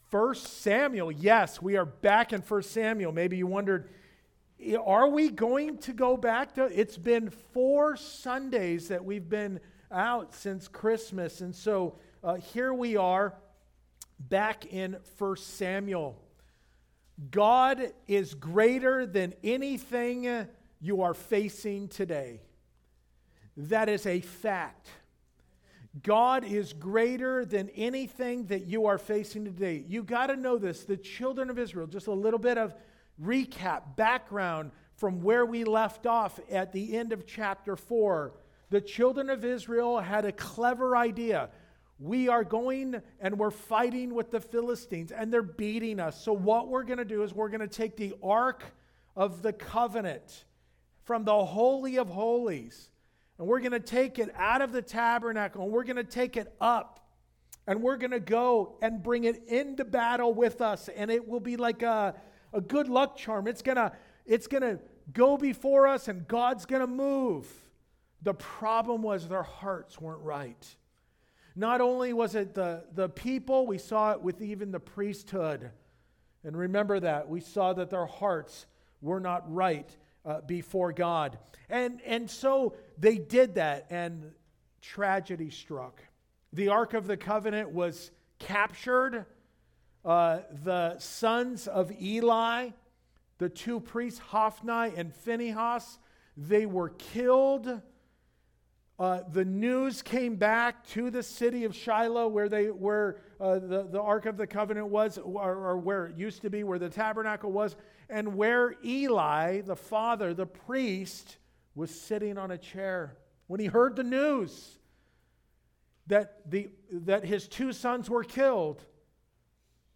God+Is+Greater+Than+Anything+You+Are+Facing+Today+2nd+Service.mp3